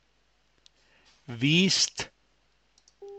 pinzgauer mundart
nach links (Pferdebefehl) wist